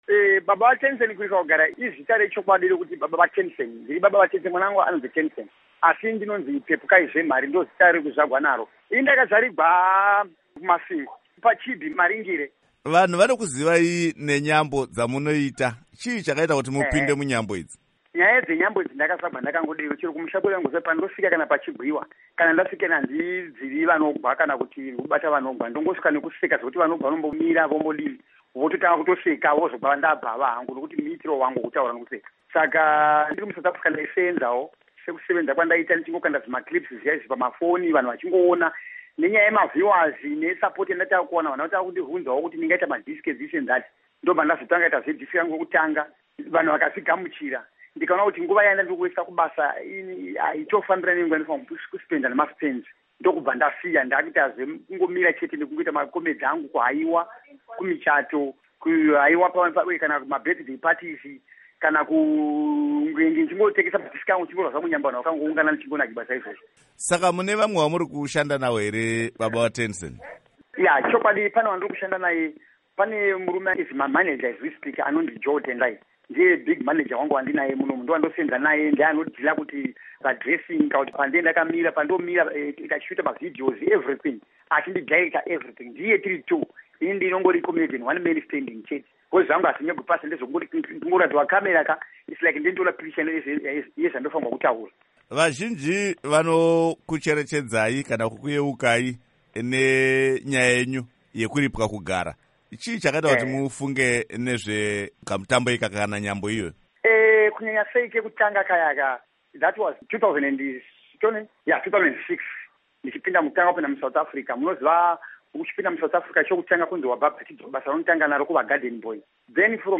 Hurukuro